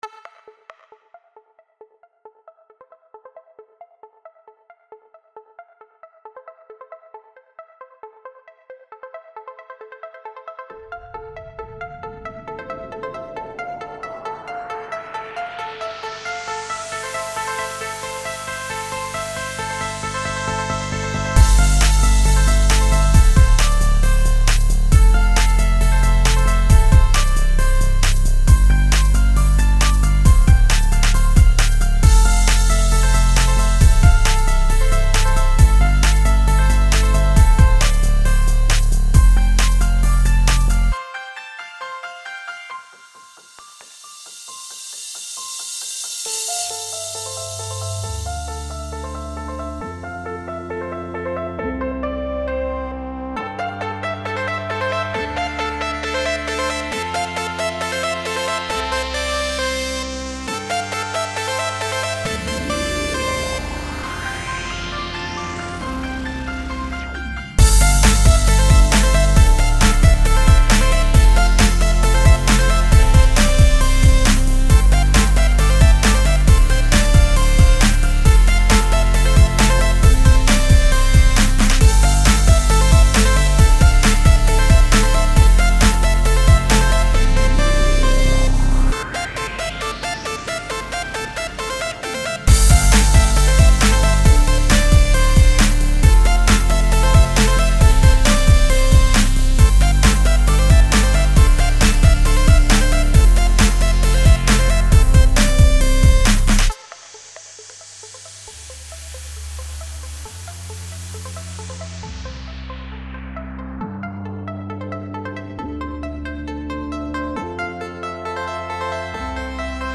Here have more movement and "danceability" xD